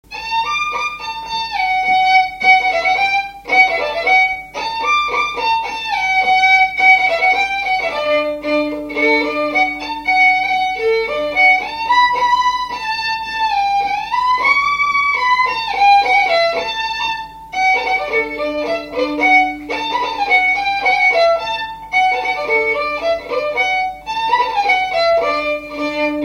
4ème figure du quadrille
danse : quadrille : pastourelle
Pièce musicale inédite